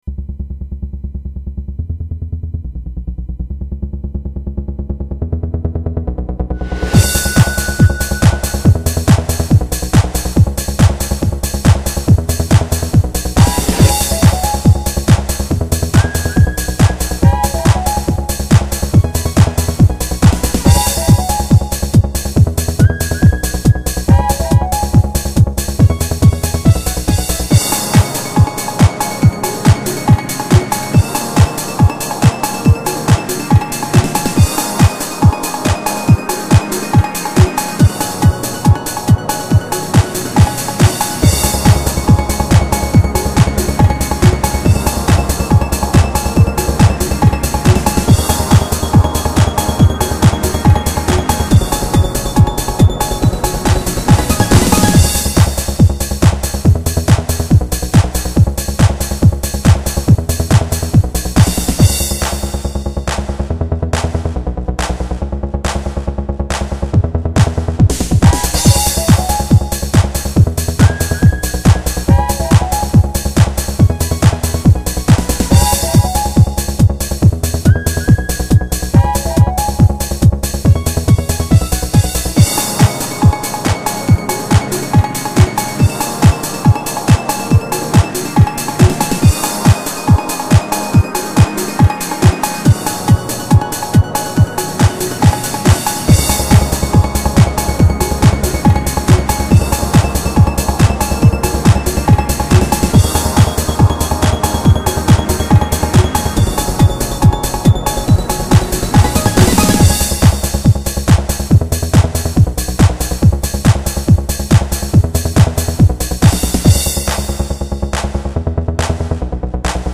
ほぼ全てがMIDI素材をMP3化しただけのものです。
ノイズも多少入ってます。
音源は基本的にハード音源のSc-8850です。
エピックトランスを目指した。